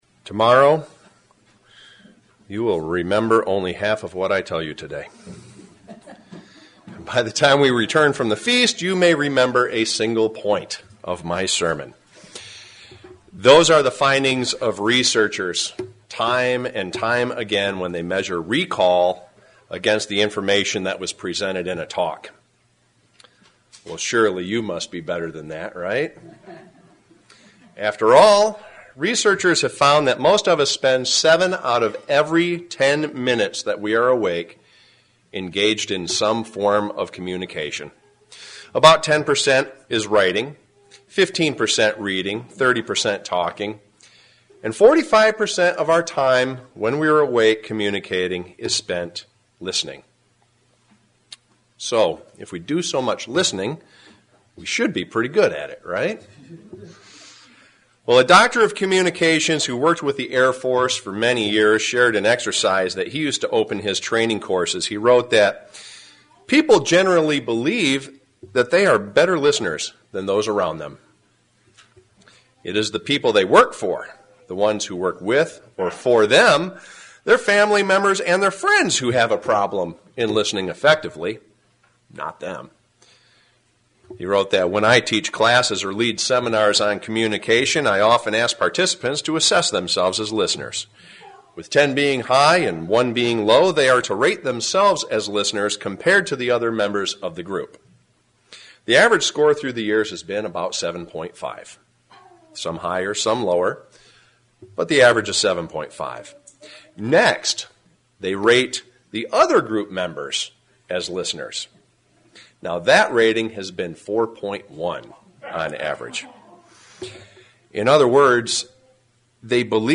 Today, I will share with you seven skills for being a better listener. sermon Studying the bible?